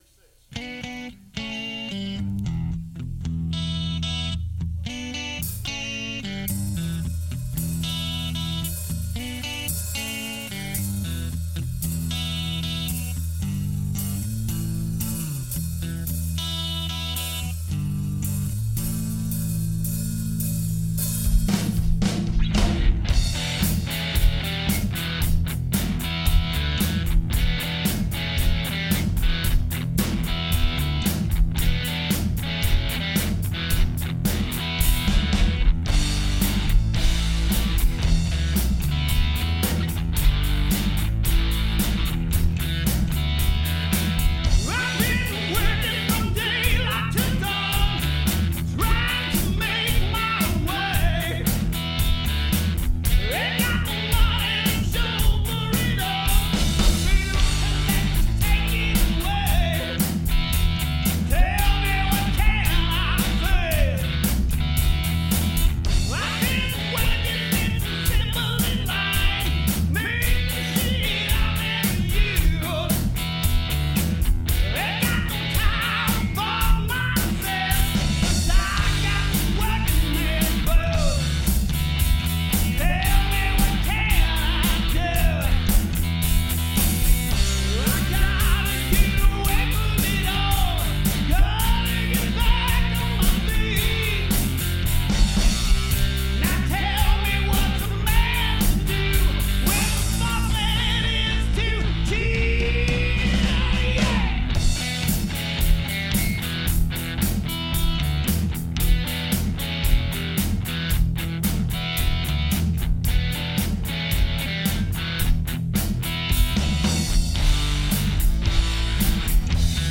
This is an original we are working on. this is the initial mix.